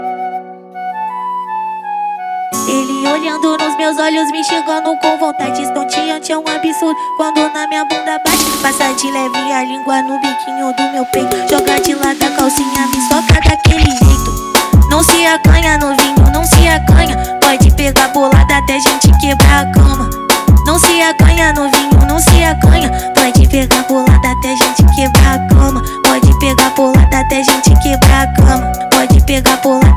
Жирный бас-клава и хлопковые гитары
Baile Funk Brazilian
Жанр: Фанк